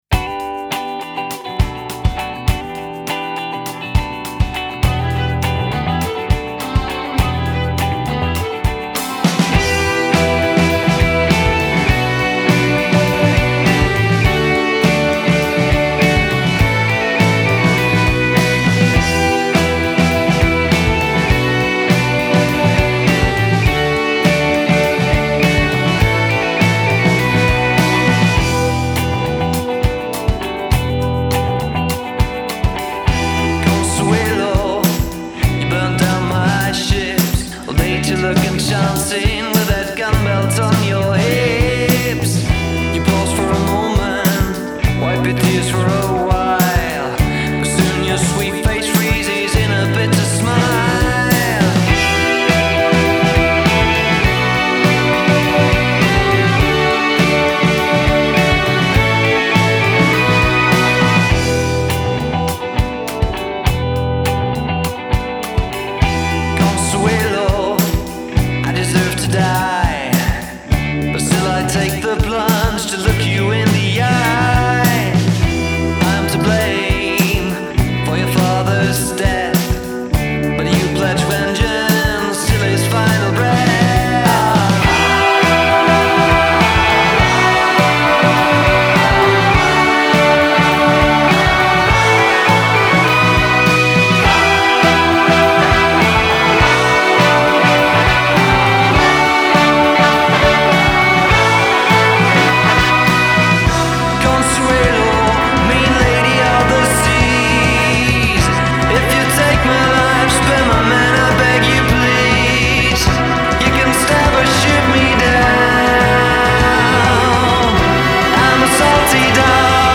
Ist das Indie, ist das Pop?